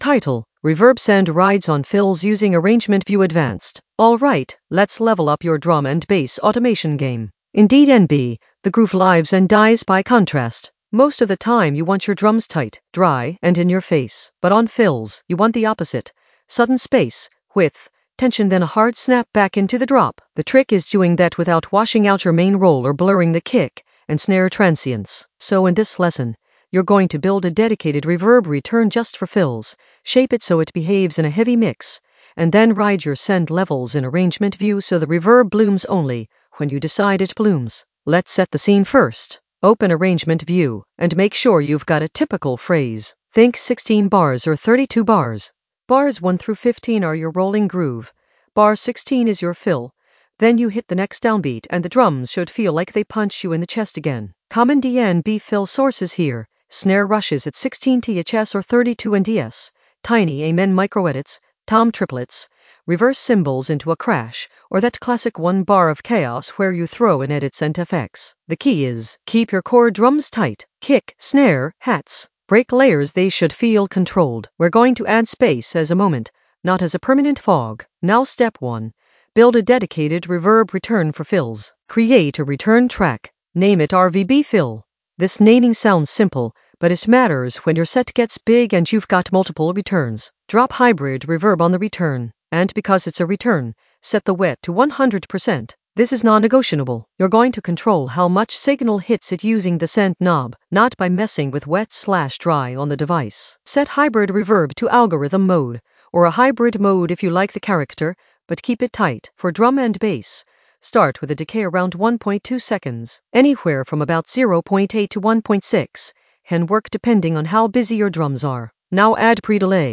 Narrated lesson audio
The voice track includes the tutorial plus extra teacher commentary.
An AI-generated advanced Ableton lesson focused on Reverb send rides on fills using Arrangement View in the Automation area of drum and bass production.